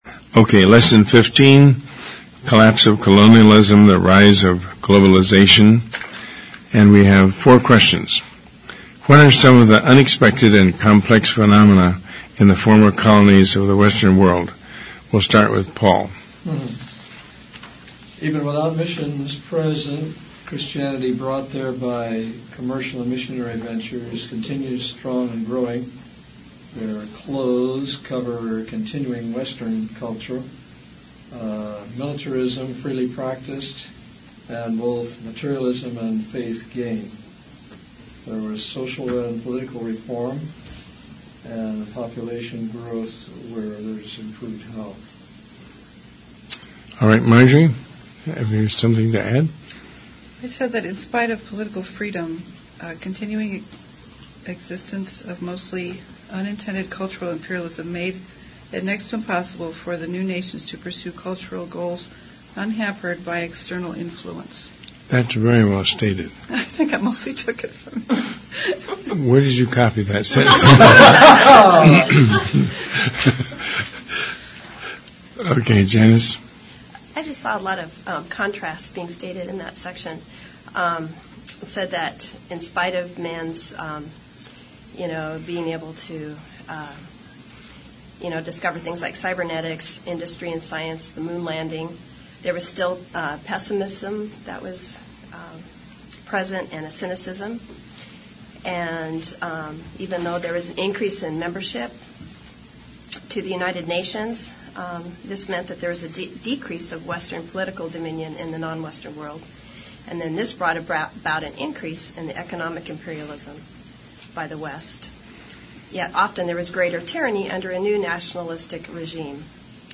lesson15-discussion.mp3